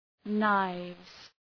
{naıvz}
knives.mp3